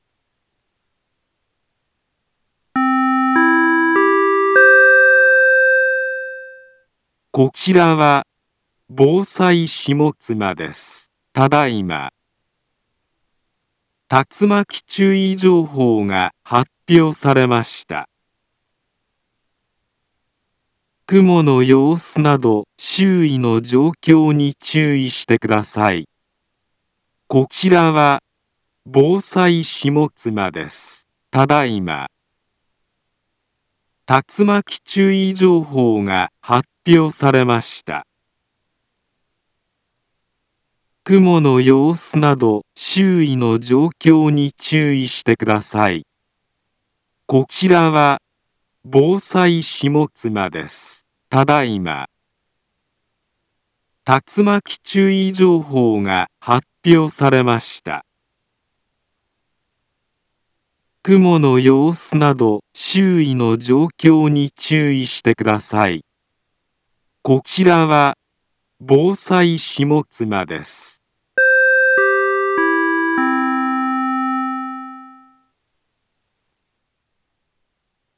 Back Home Ｊアラート情報 音声放送 再生 災害情報 カテゴリ：J-ALERT 登録日時：2025-08-08 15:04:47 インフォメーション：茨城県北部、南部は、竜巻などの激しい突風が発生しやすい気象状況になっています。